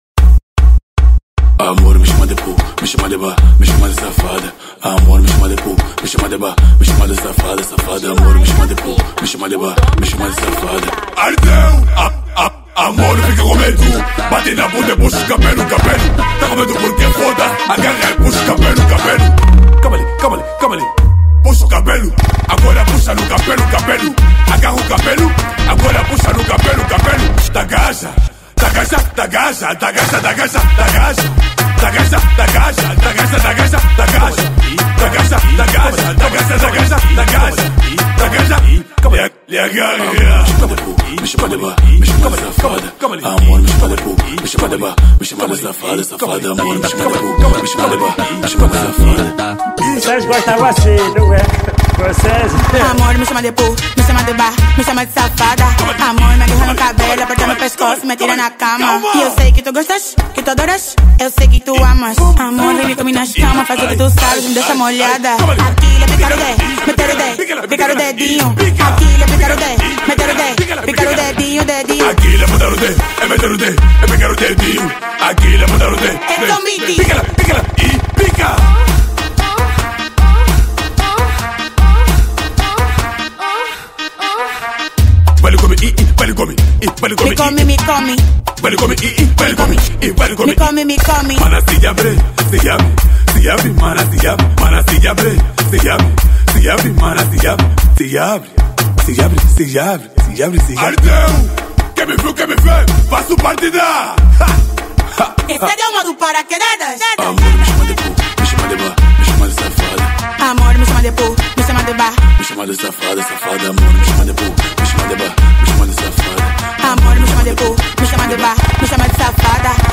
Afro House 2025